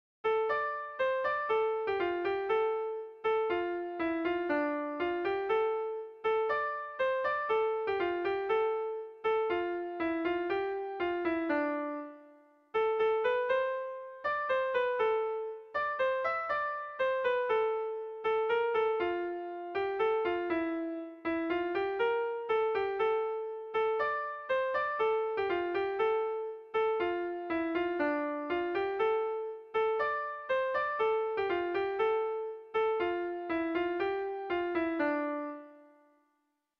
Erlijiozkoa
AB1AB2